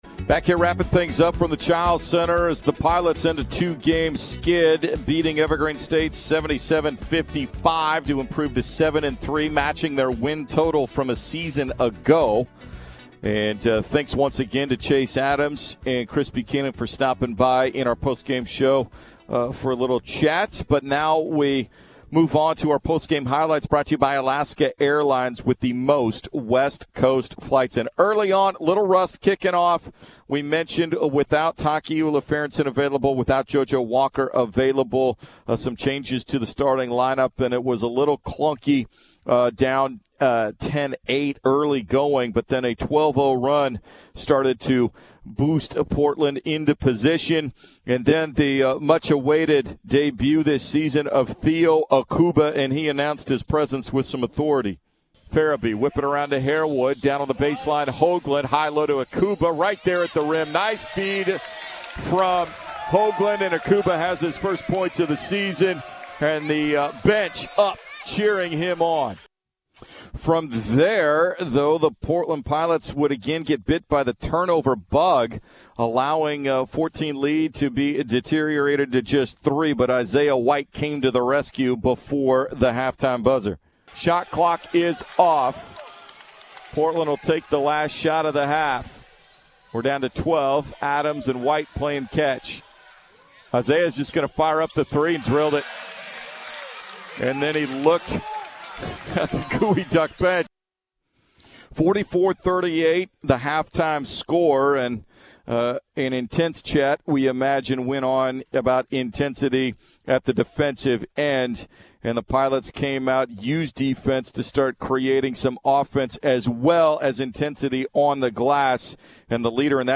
December 13, 2019 Portland defeated Evergreen State 77-55 on Dec. 13 to improve to 7-3 on the season. Here are the post-game radio highlights.